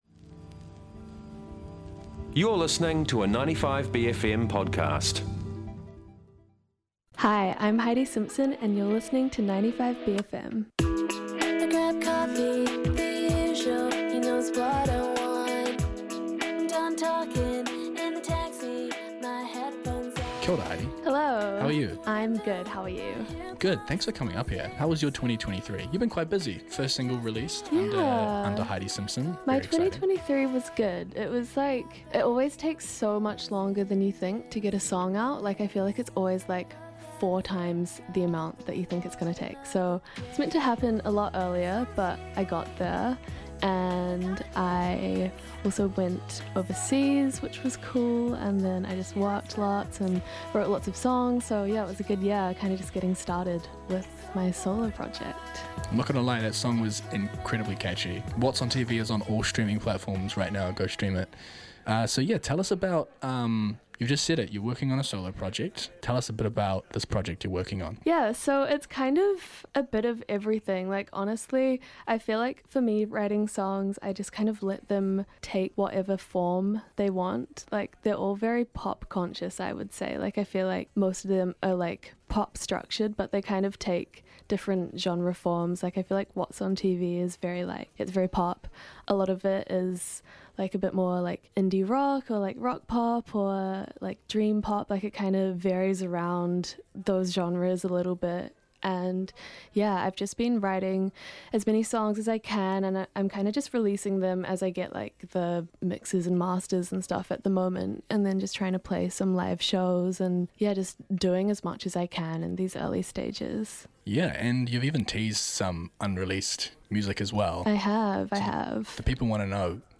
A kōrero w